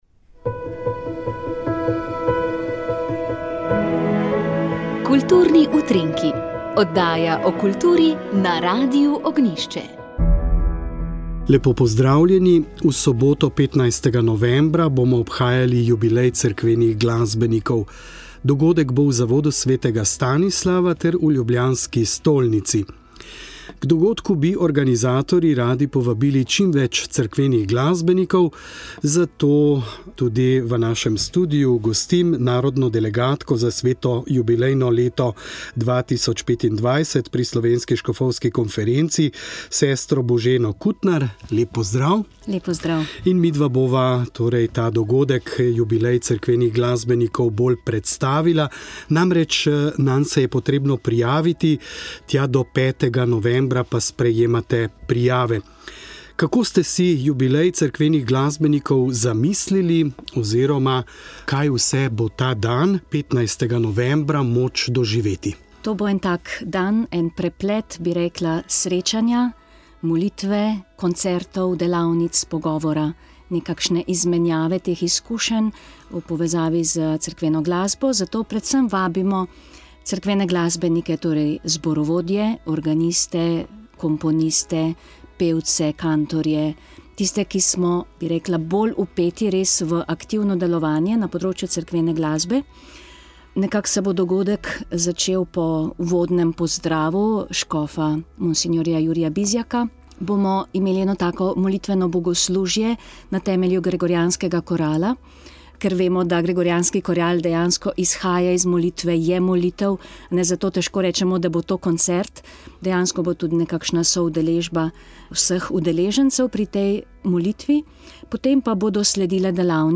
pridiga